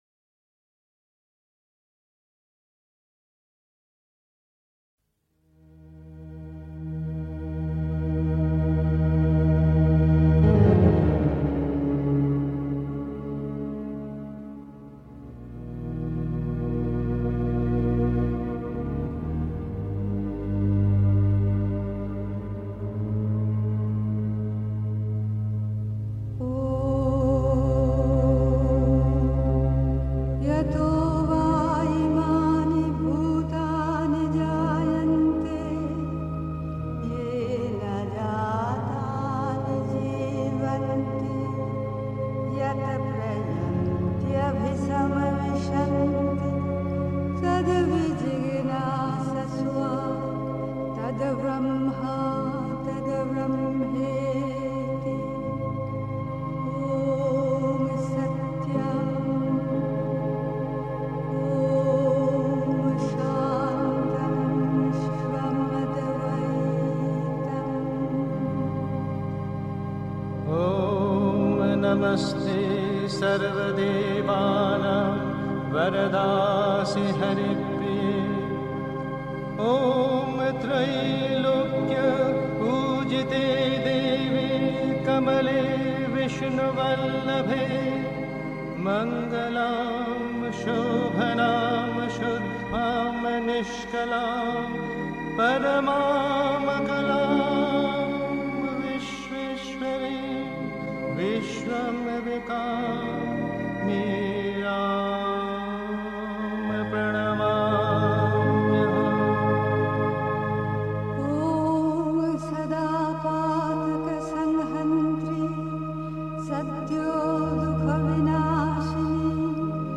1. Einstimmung mit Sunils Musik aus dem Sri Aurobindo Ashram, Pondicherry. 2. Schlaf (Die Mutter, White Roses, 07 March 1956) 3. Zwölf Minuten Stille.